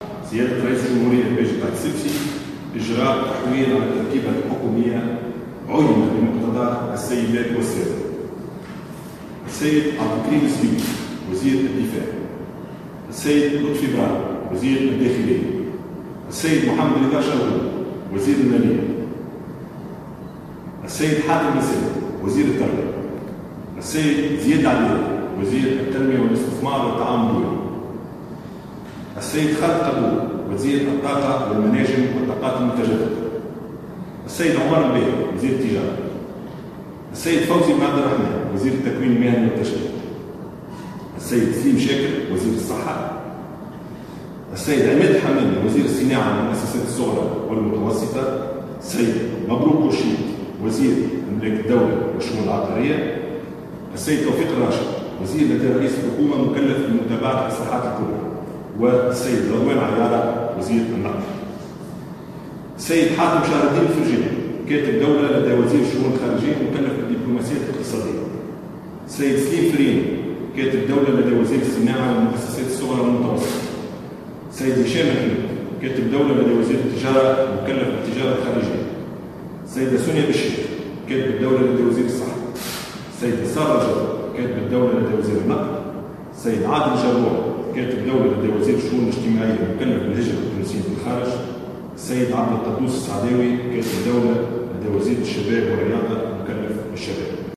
أعلن رئيس الحكومة يوسف الشاهد اليوم الأربعاء 6 سبتمبر 2017، عن تحوير وزاري جزئي شمل 13 وزارة و7 كتاب دولة في تركيبة حكومته، خلال كلمة ألقاها في قصر قرطاج الرئاسي.